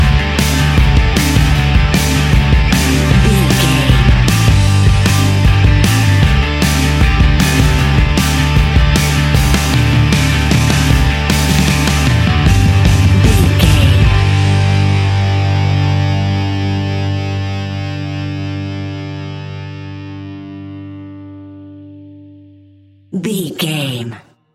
Epic / Action
Fast paced
Ionian/Major
hard rock
distortion
Rock Bass
heavy drums
distorted guitars
hammond organ